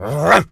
dog_large_bark_04.wav